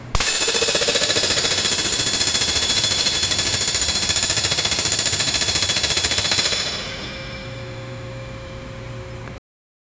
Royalty-free destruction sound effects
A sharp, high-speed slam of propeller blades, accompanied by the crisp, cracking sound of the plastic casing shattering. A swift and powerful impact, like a plastic drone slamming into a wall.
a-sharp-high-speed-slam-o-h4yyyu35.wav